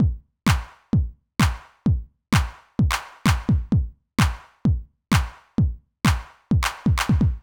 INT Beat - Mix 6.wav